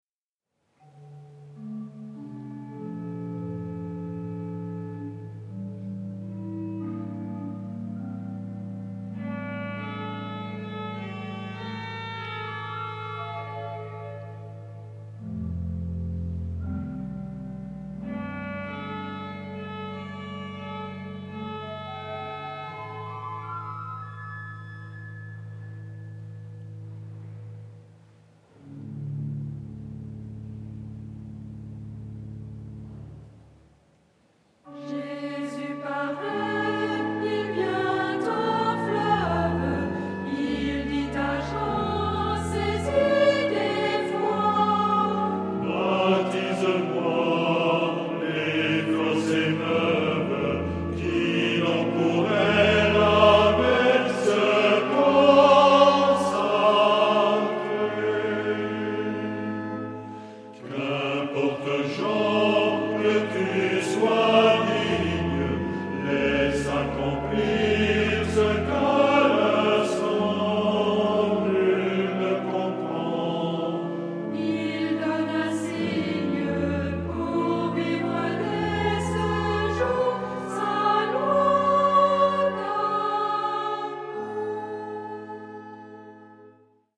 Genre-Style-Form: hymn (secular)
Mood of the piece: calm
Type of Choir:  (1 unison voices )
Instrumentation: Organ  (1 instrumental part(s))
Tonality: D minor